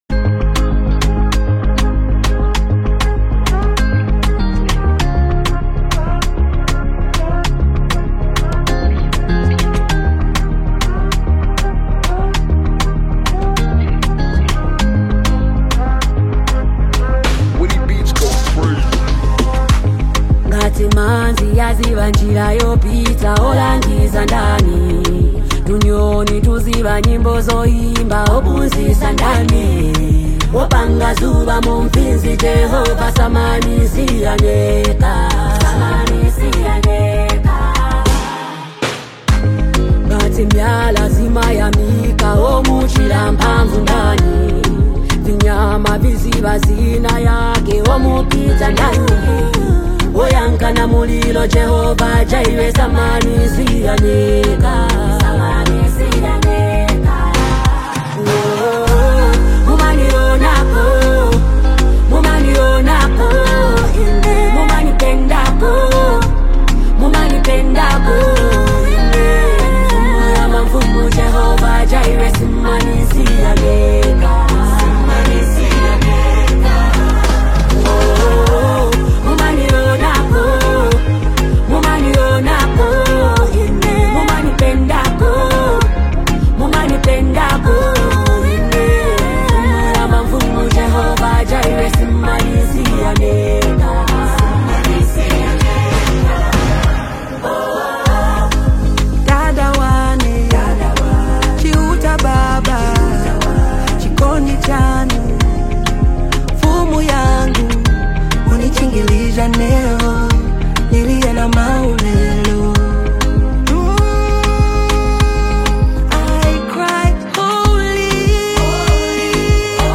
Latest Zambian music